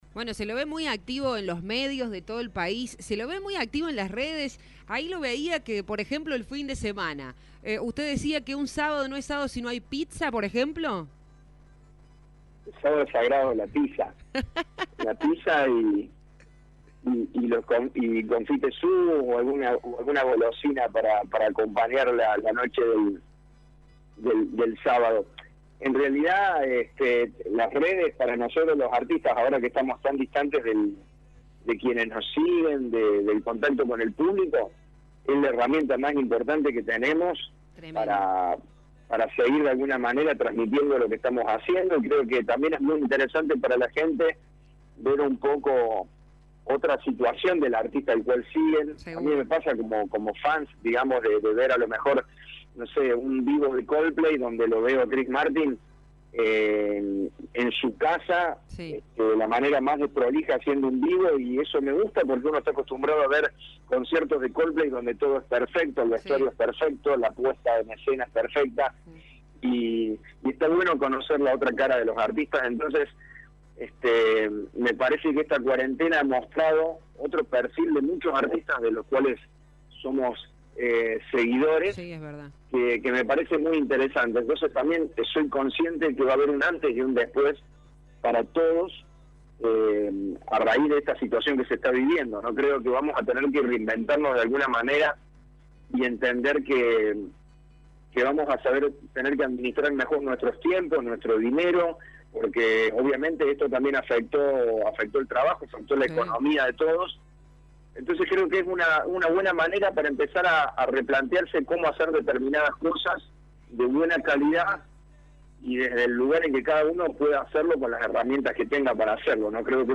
lo reflejó en la entrevista con Radio Show.